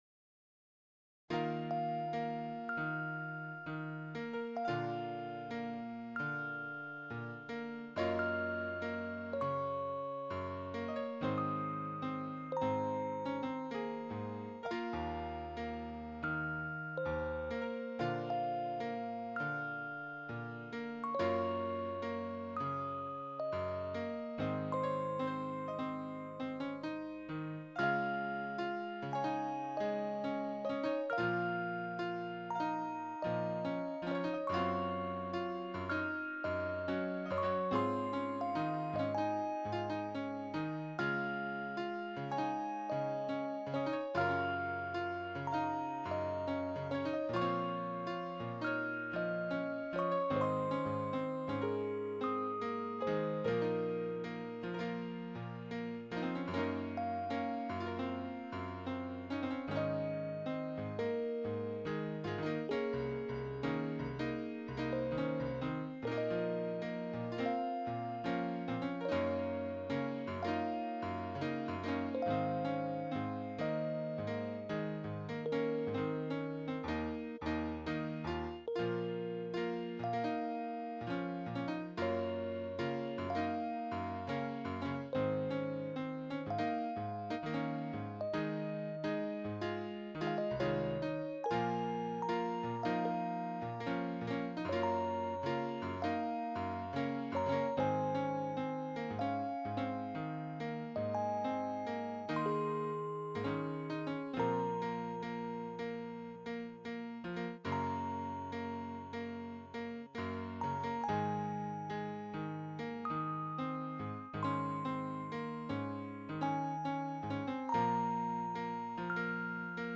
Piano Melody With Electric Piano second version